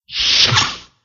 door.ogg